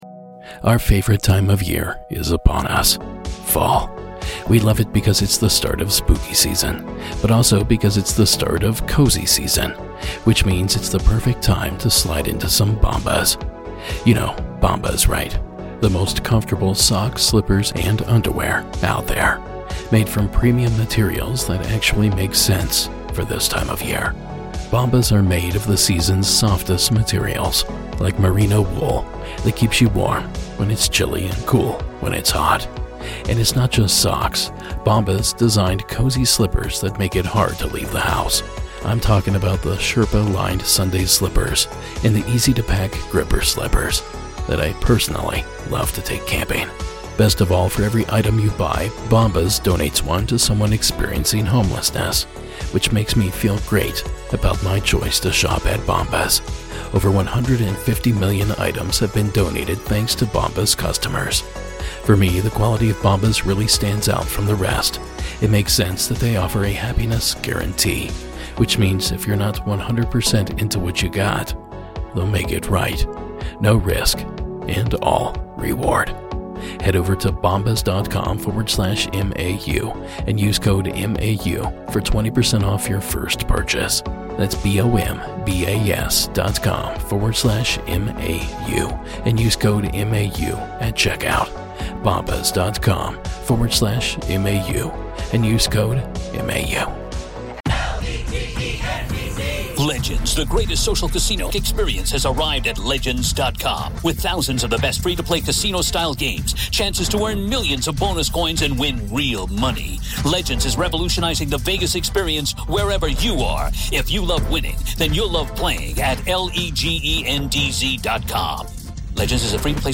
Welcome to the Season 17 premiere, the government worker special! All of the stories shared tonight are from callers who work or have worked for the government, and they sure brought the spooky!